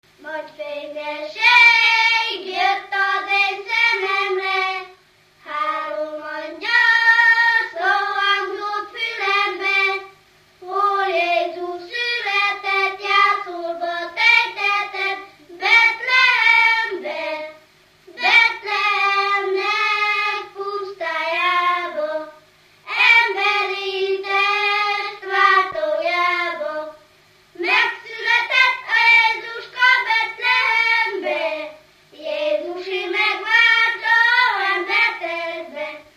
Alföld - Szatmár vm. - Nagyecsed
Műfaj: Karácsonyi csillagjárás
Stílus: 7. Régies kisambitusú dallamok